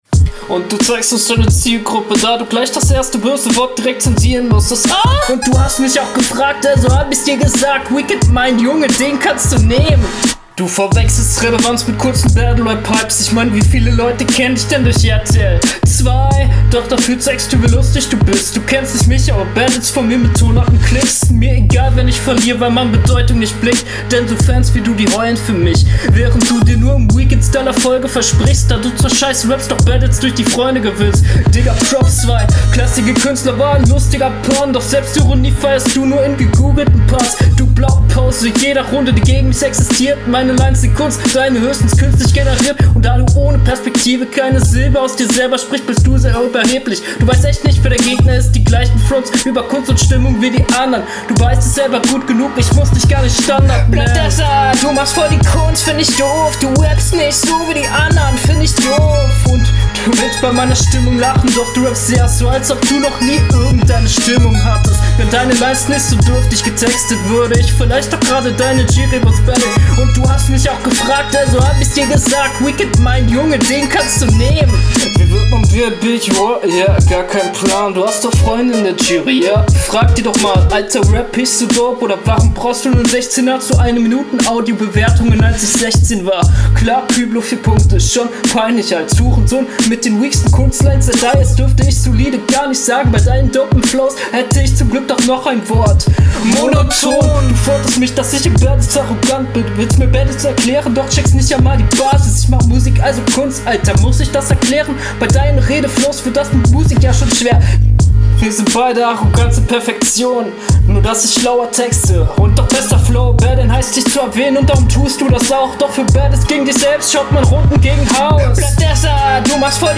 Deine Quali klingt etwas schlechter.
Flowlich in etwa gleichwertig.